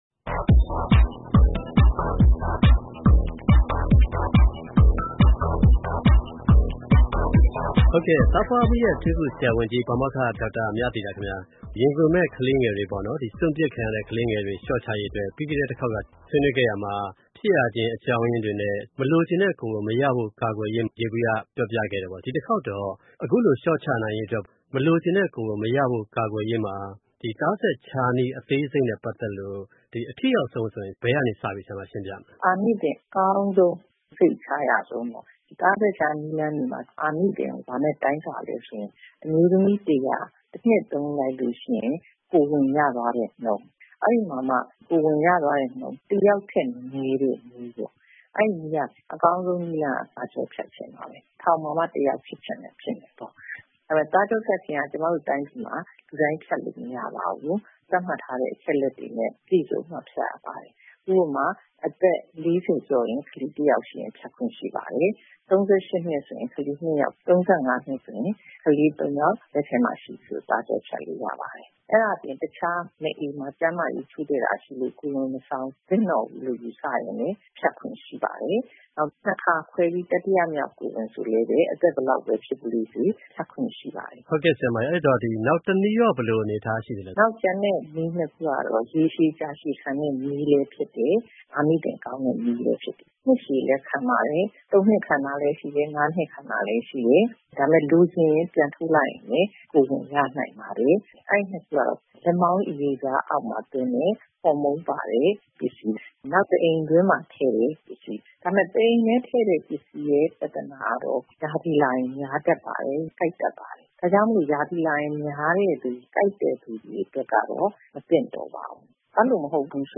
ဆက်သွယ်မေးမြန်း ဆွေနွေးတင်ပြထားပါတယ်။